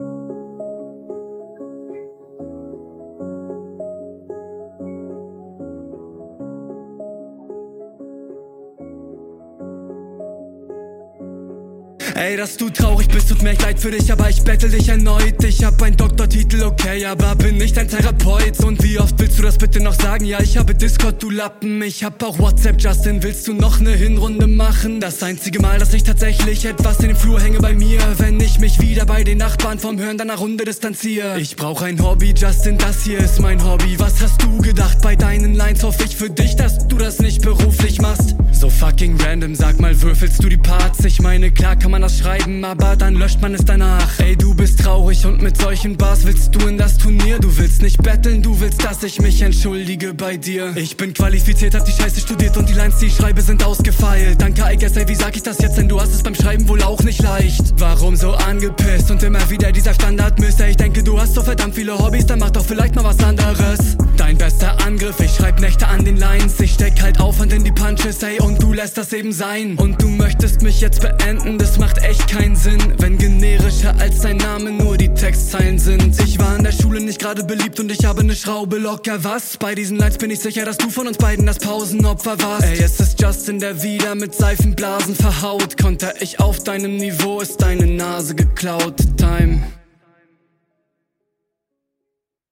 - Starker Beatpick der zum Stimmbild passt und DEUTLICH bessere Mische für deine Stimme, lak …
(Wirklich geiles Battle Jungs) Der Beat ist Atmosphärisch, die Flow-Pattern gefallen mir sehr, …